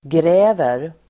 Ladda ner uttalet
Uttal: [gr'ä:ver]